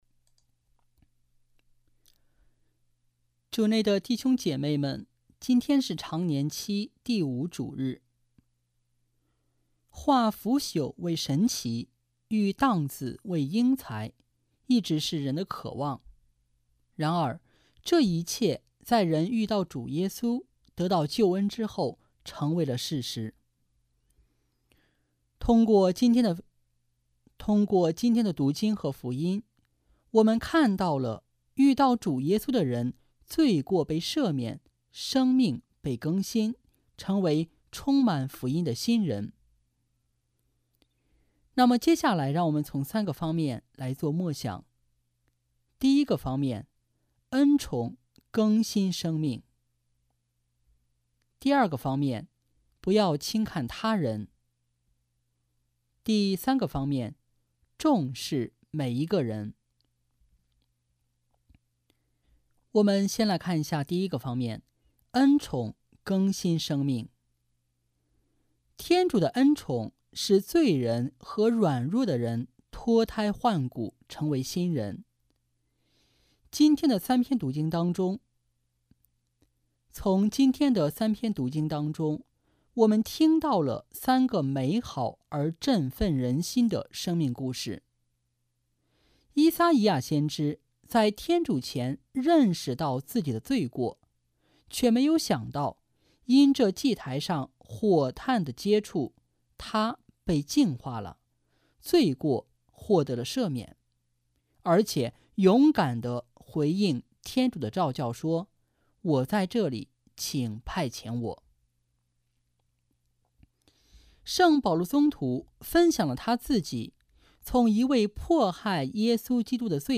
【主日证道】| 爱使朽木成栋梁（丙-常年期第5主日）